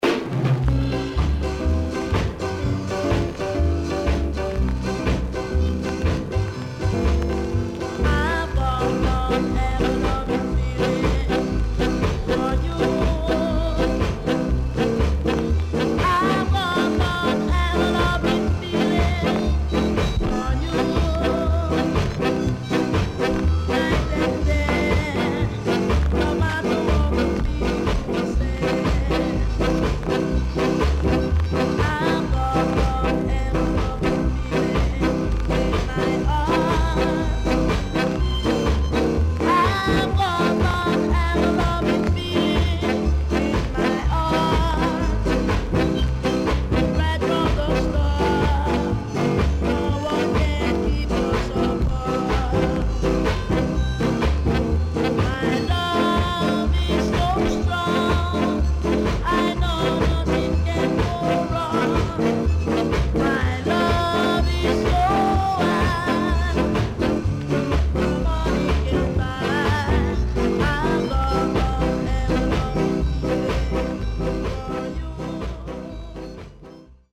CONDITION SIDE A:VG(OK)
W-Side Good Ska Vocal
SIDE A:うすいこまかい傷ありますがノイズあまり目立ちません。